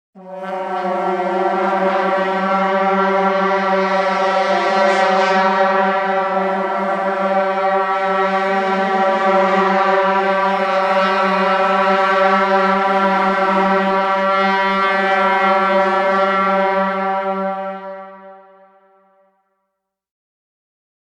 Здесь вы можете слушать и скачивать разные варианты гула: от монотонного гудения до интенсивного рева.
Гудок стадионного сигнала